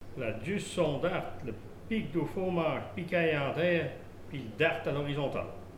Mémoires et Patrimoines vivants - RaddO est une base de données d'archives iconographiques et sonores.
Il aiguise la faux ( selon l'une des trois façons d'aiguiser )